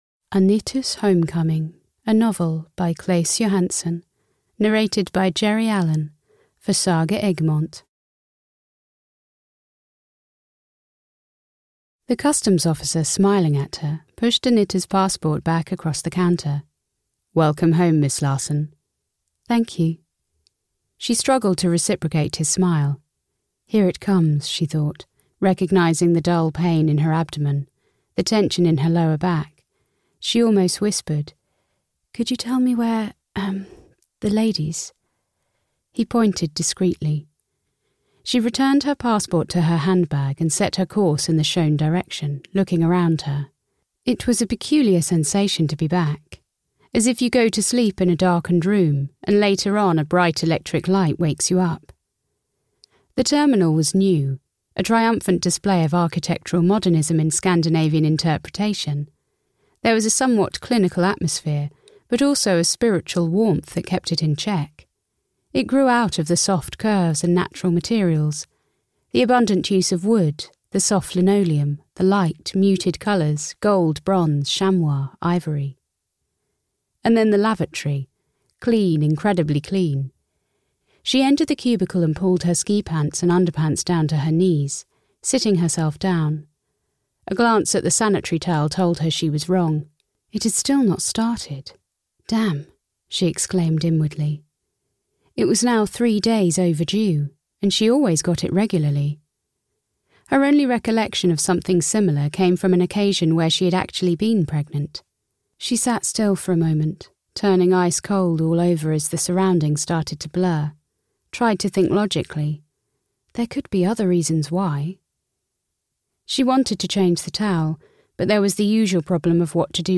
Anita’s Homecoming (EN) audiokniha
Ukázka z knihy